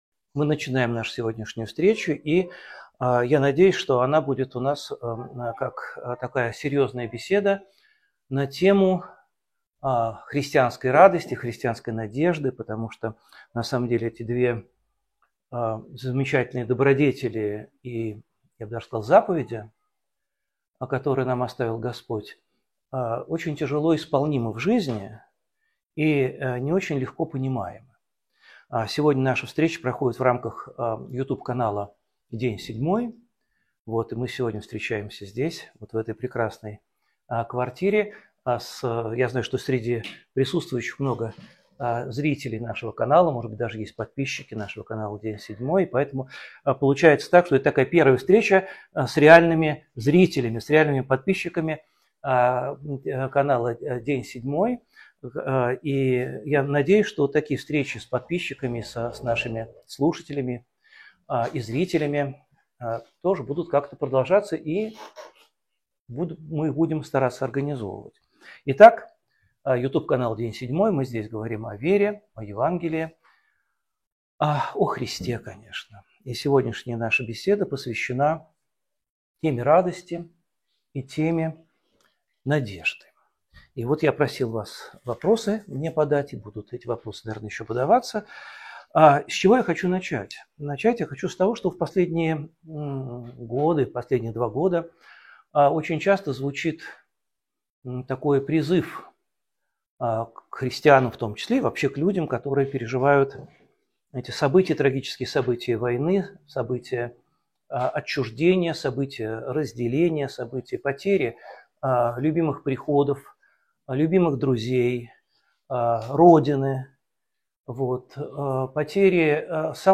«День седьмой» с Алексеем Уминским: Разговор о радости и надежде